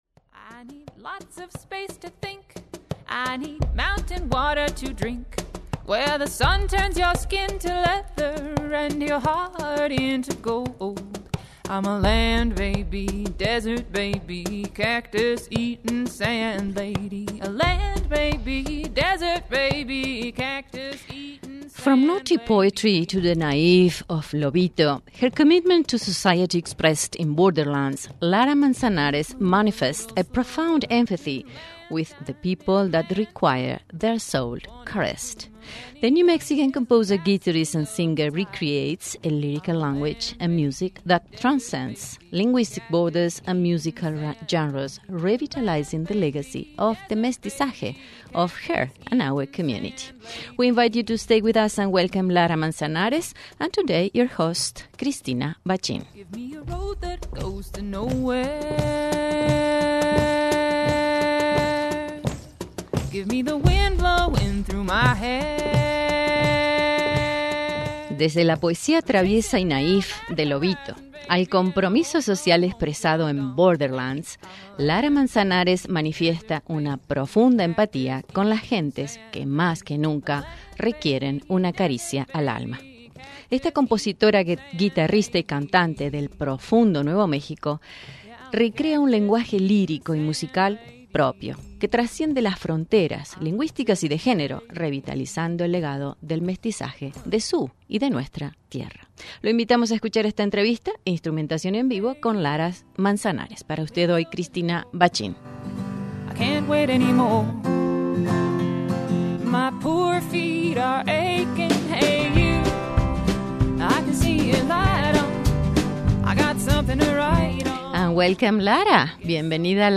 LISTEN HERE to live performance and interview